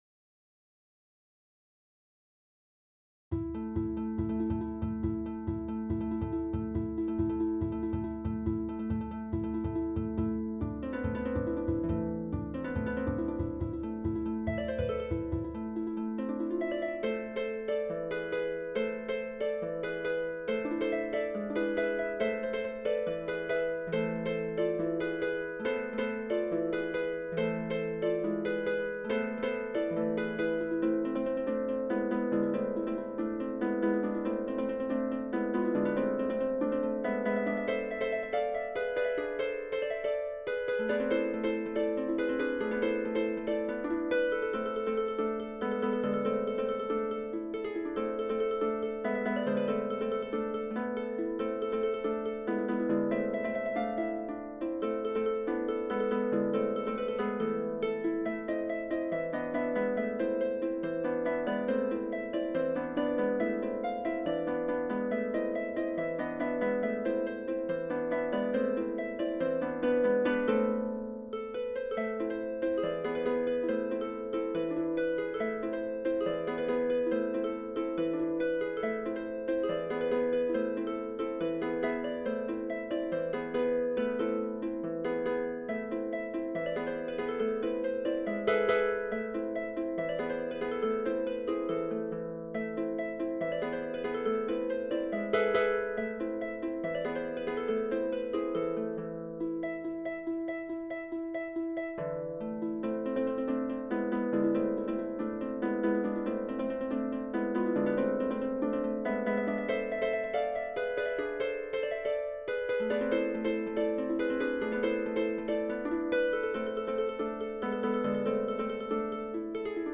パート編成 箏二重奏
箏二重奏mp3